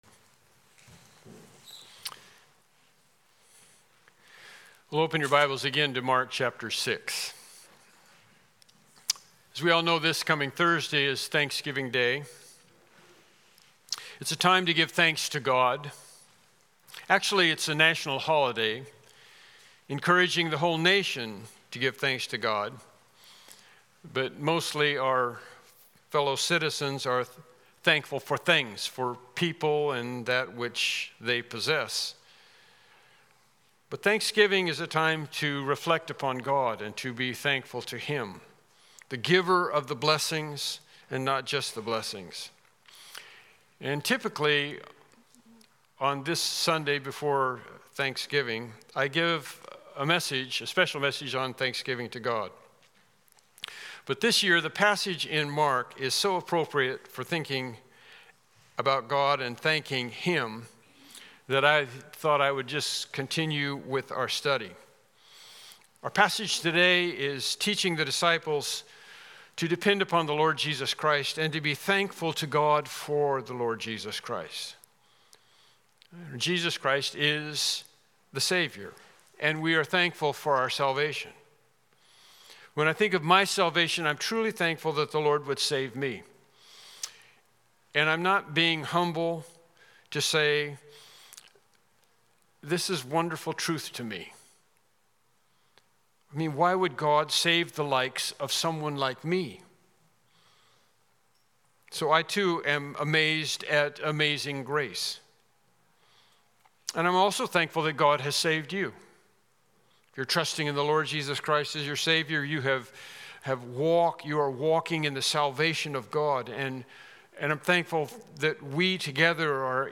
Mark Passage: Mark 6:45-52 Service Type: Morning Worship Service « Lesson 12